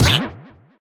failgroan.ogg